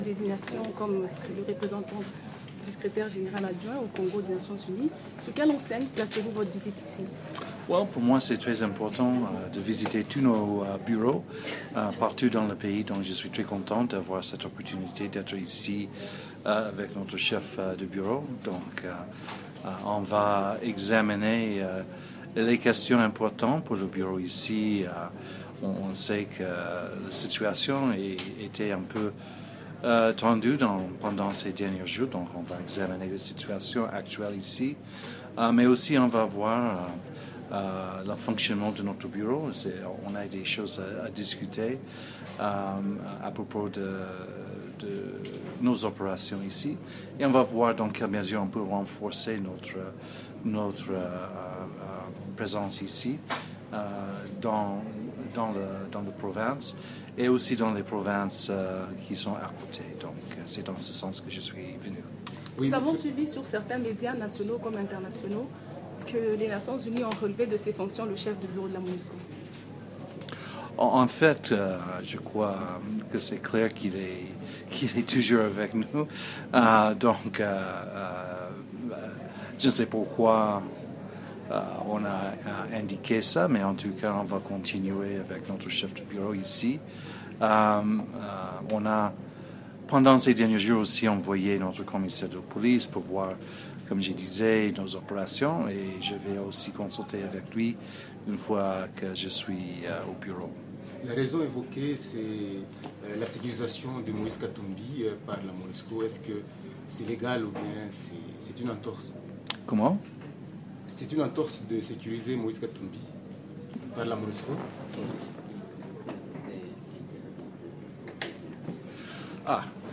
Point presse Monusco, à Lubumbashi